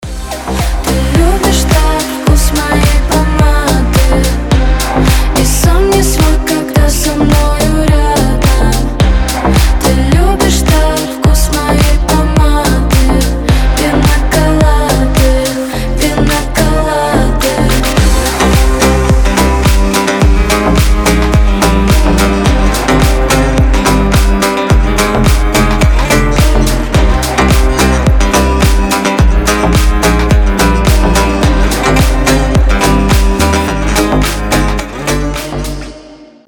• Качество: 320, Stereo
поп
женский вокал
deep house
восточные мотивы